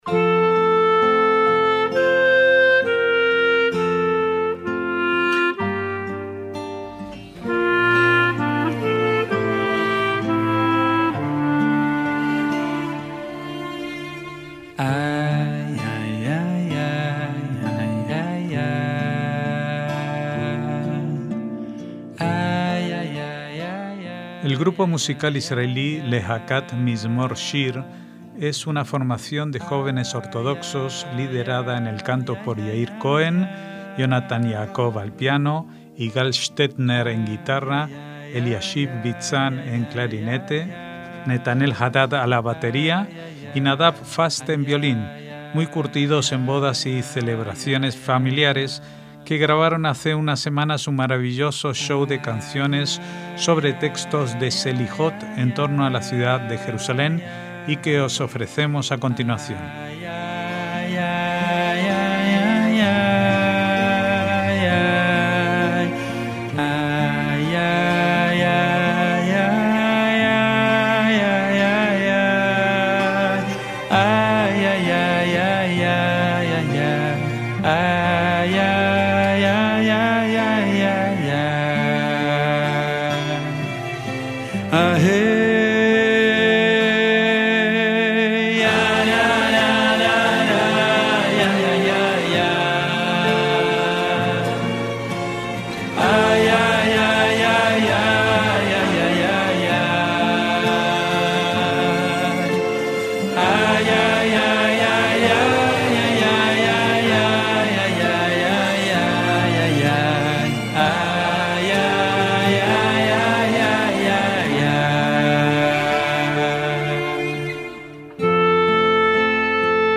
MÚSICA ISRAELÍ
es una formación de jóvenes ortodoxos
piano
guitarra
clarinete
batería
violín